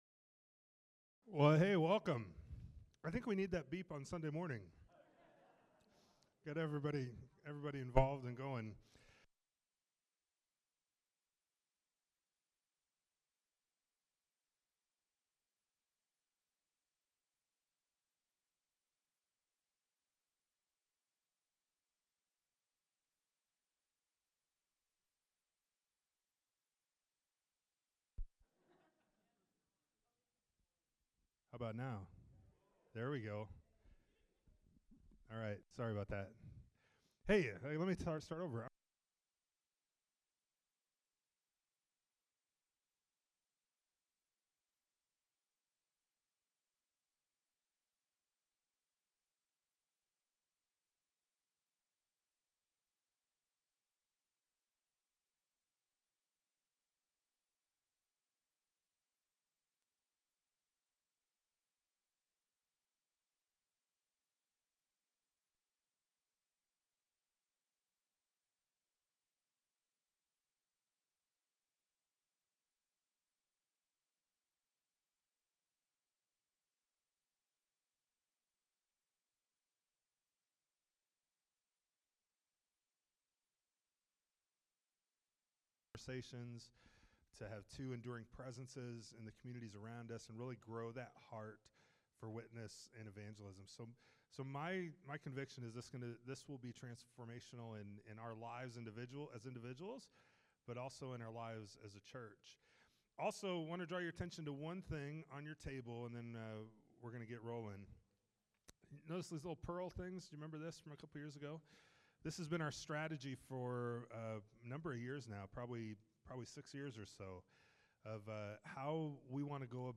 " Evangelism Shift " Seminar recordings from Evangelism Shift.